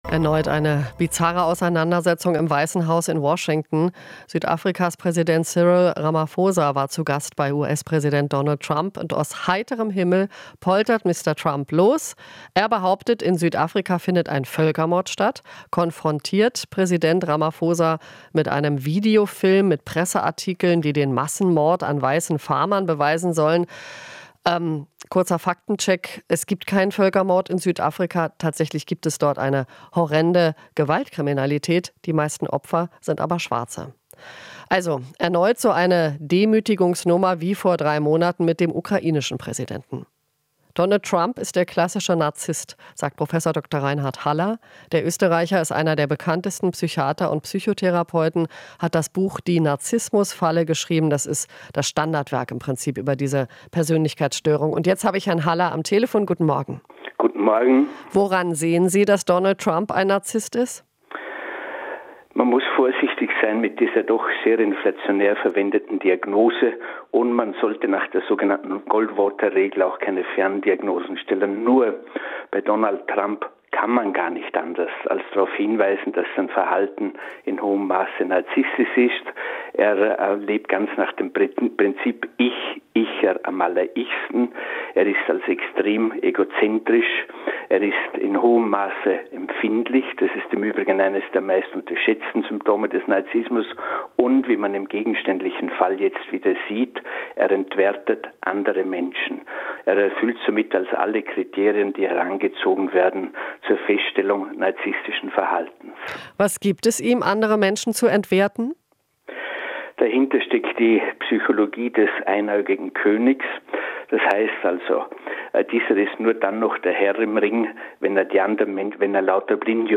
Interview - Psychiater Haller: Trump erfüllt alle Kriterien eines Narzissten